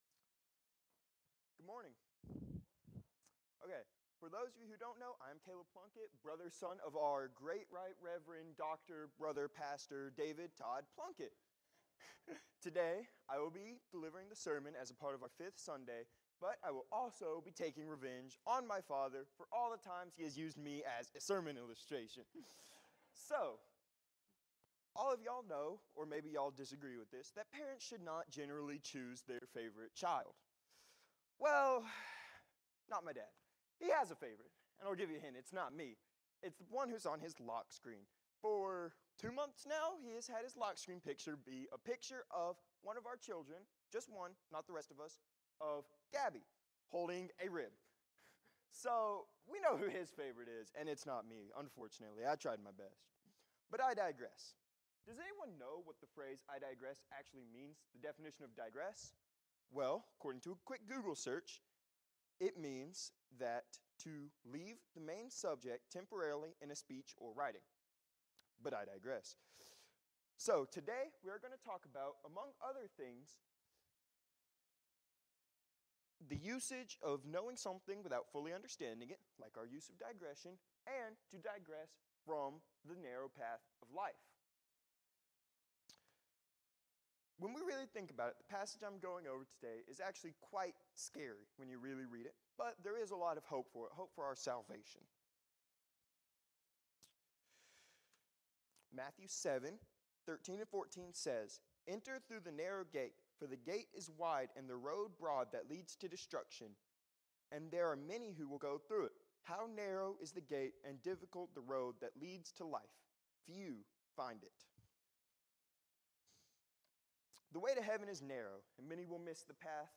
Sermons | Eastwood Baptist Church